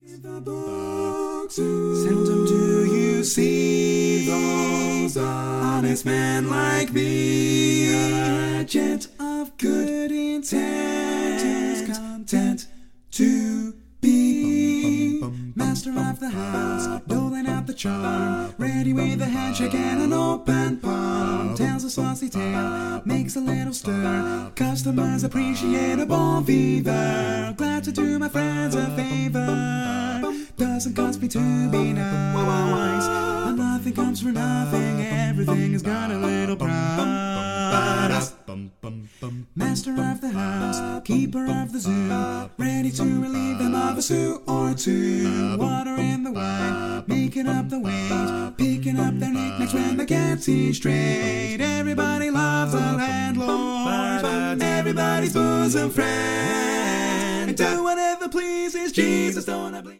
Category: Male